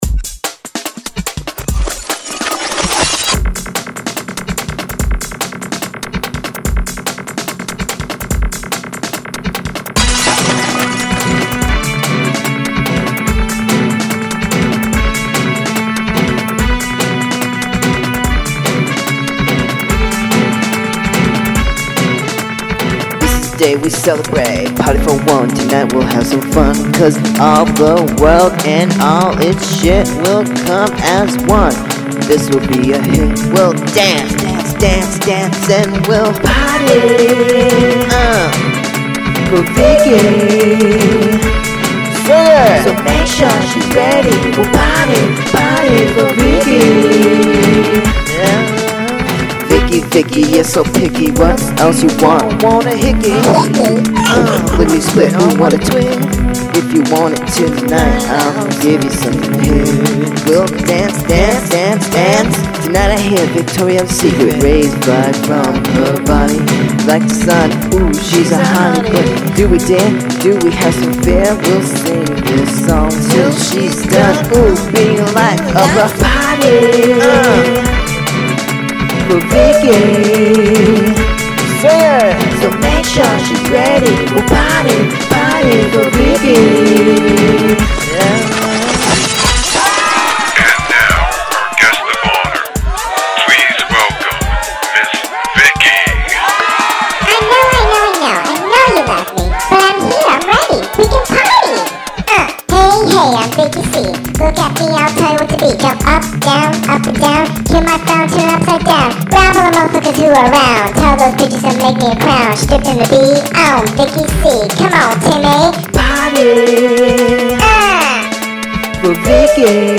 I think u'll enjoy it. listen to it, feel the build up of music.
that was a funny song!
It's a groovy loop zilla groove. Very experimental I like it.
The biggest weakness I feel is the microphone. It doesn't have a very wide range, and the p's are popping - (too close to the mic/no windscreen?). Everything else is so crystal clear and the vocals are coming through a spaghetti strainer, comparatively.
Then the piano/guitar part after that is awesome.
Cool screams.
I sometimes make my own beats with my guitar keyboard which is hooked up to a E-mu module. but this song and strawberry flow was drum samples i got from some site.